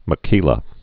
(mə-kēlə, mä-kēlä)